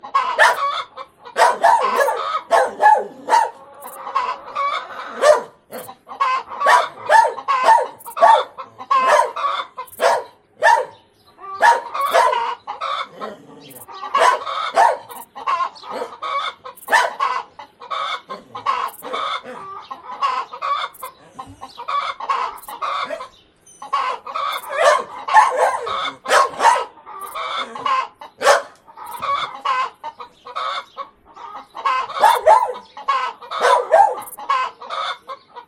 Собака гавкает на ферме